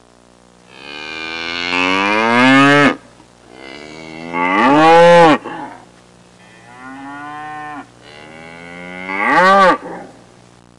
Cows Sound Effect
Download a high-quality cows sound effect.
cows.mp3